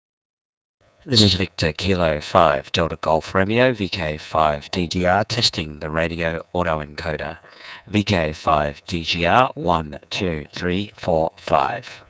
以下はデコード後の受信音声サンプルです。
デコードされたRADE V2
デコードされたV2の音声は、RADE V1とほぼ同じように聞こえます
（SSBよりも良い音質です）。